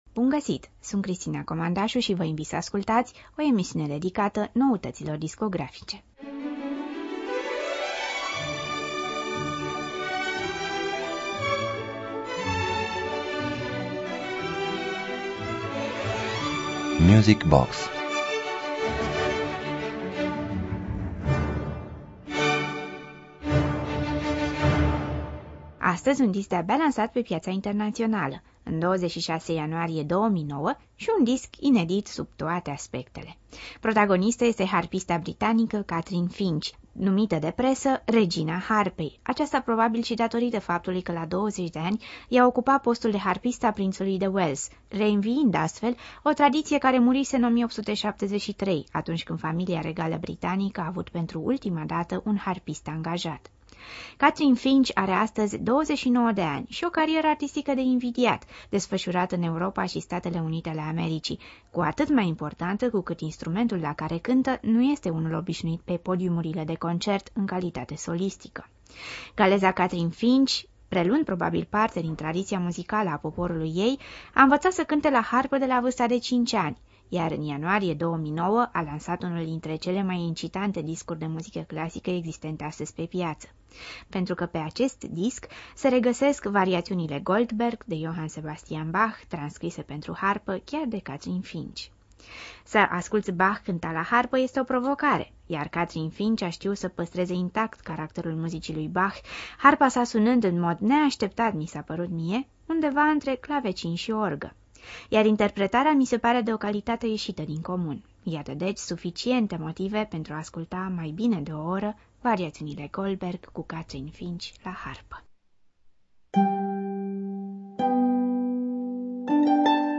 harpa